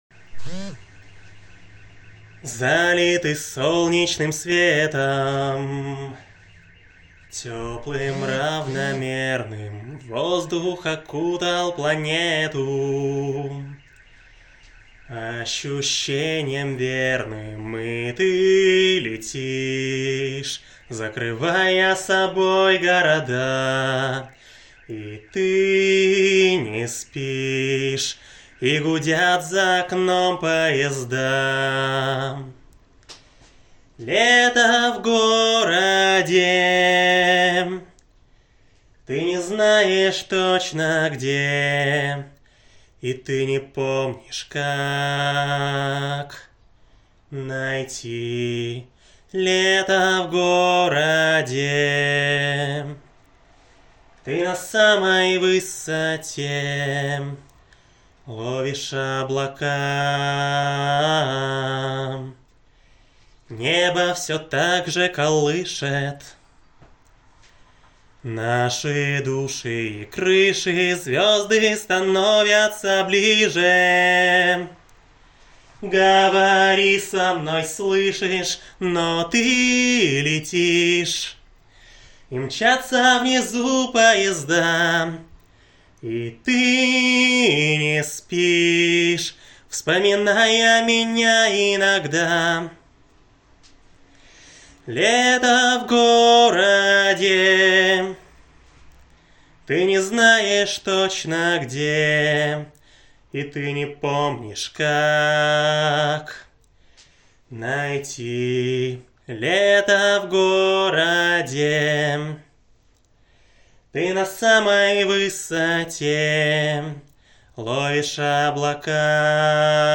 Зацените мой сегодняшний вокал.
ну без музики неинтересно же, так-то тембр приятний